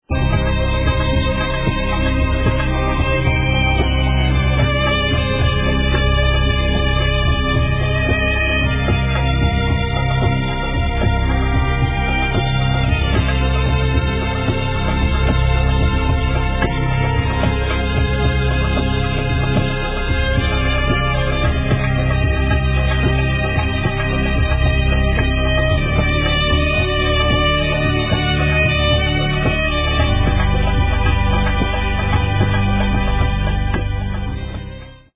kawalaa reed-flute typical of Egyptian folk music
nayan Arab reed-flute
'uda Middle Eastern short-necked plucked lute